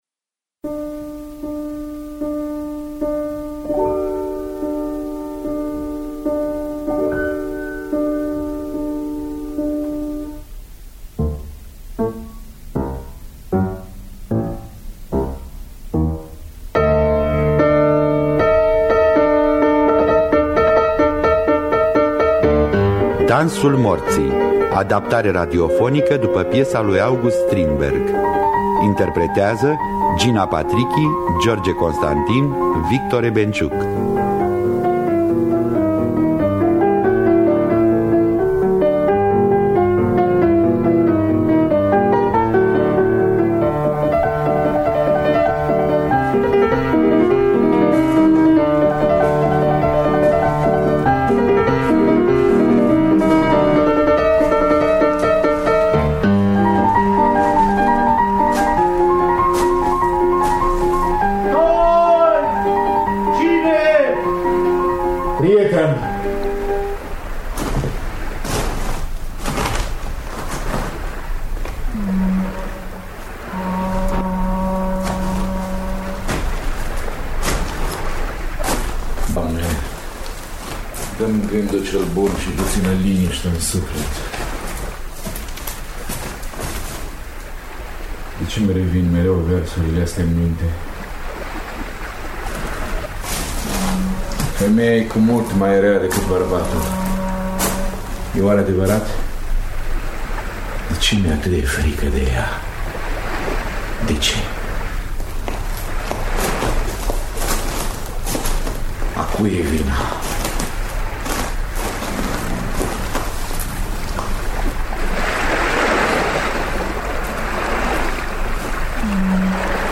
Înregistrare din anul 1978.